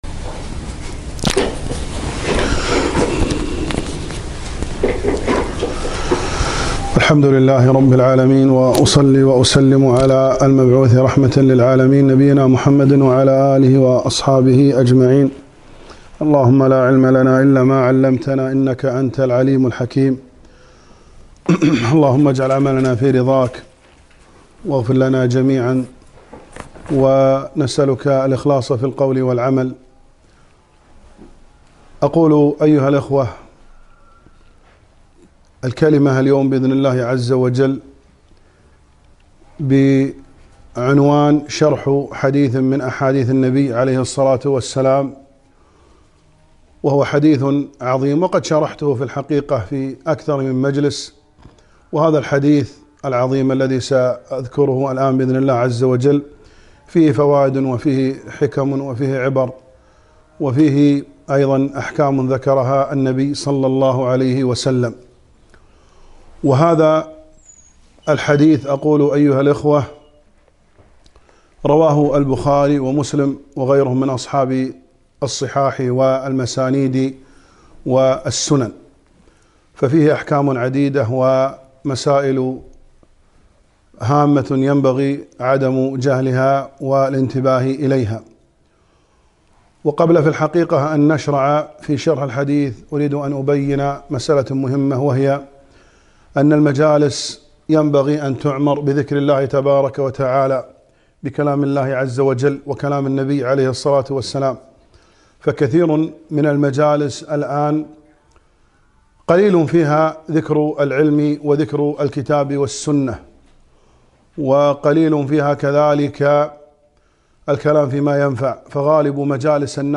محاضرة - قال النبي ﷺ سبعة يظلهم الله في ظله